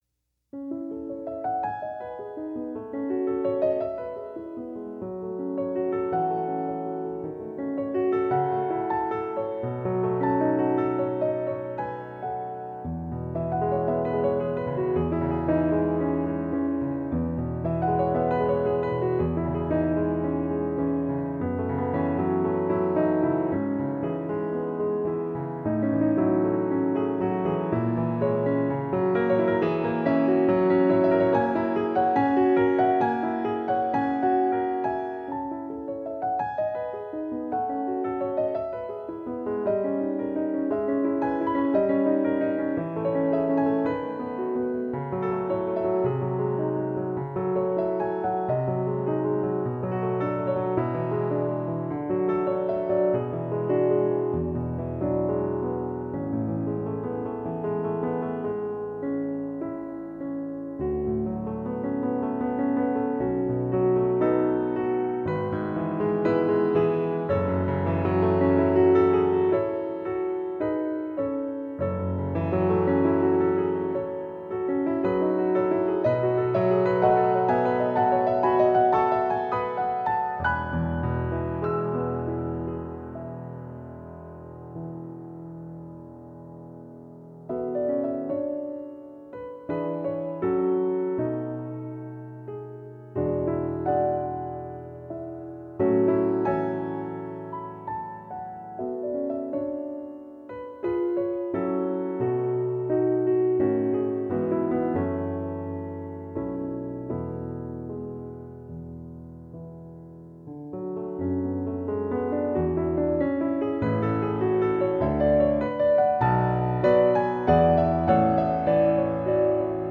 موسیقی کلاسیک Claude Debussy _ Arabesque No. 1